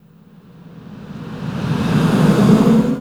SIGHS 2REV-R.wav